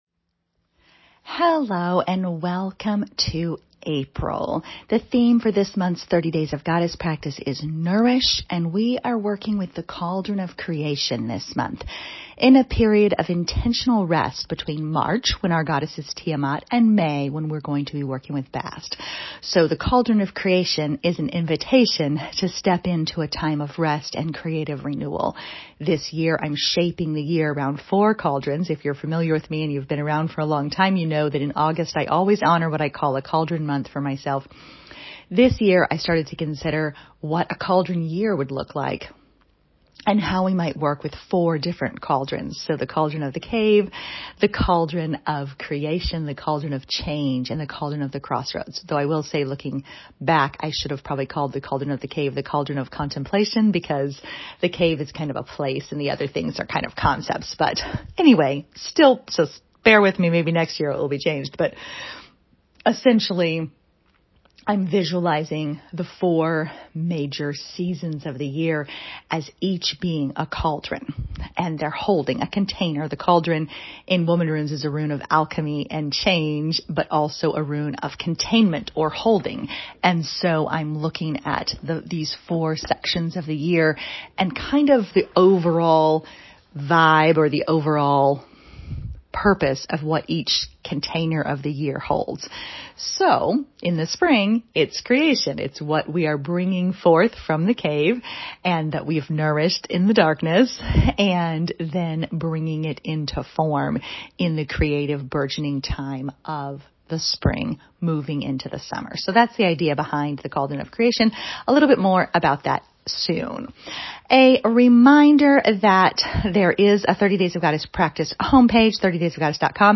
This month’s audio welcome message is embedded directly above AND your free practice resources are available as downloadable files, along with many other resources, in the #30DaysofGoddess newsletter.